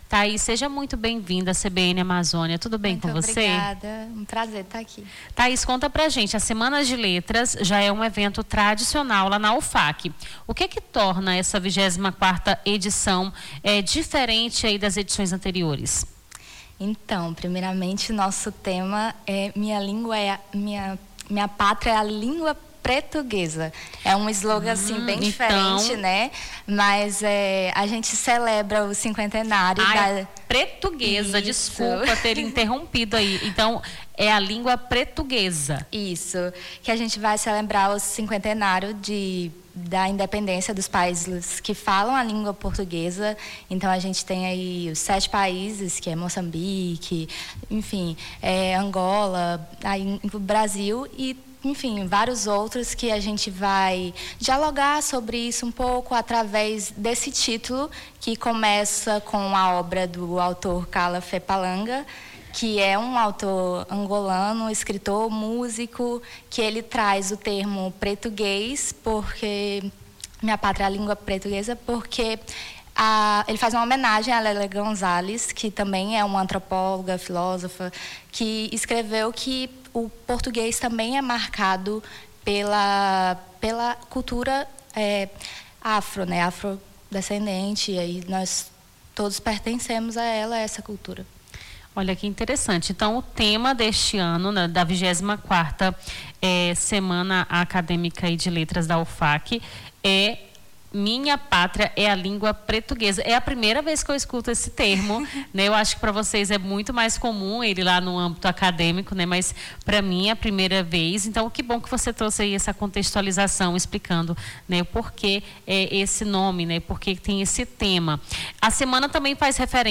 Nome do Artista - CENSURA - ENTREVISTA (XXIV SEMANA DE LETRAS MINHA PATRIA E A LINGUA PRETUGUESA) 07-11-25.mp3